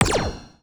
UIClick_Menu Laser Gunshot.wav